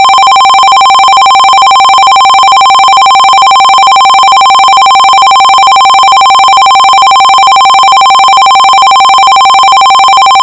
Electronic Bell Ring:
Fun Fact but the Electronic Bell Ring is used in cheap telephone ringers.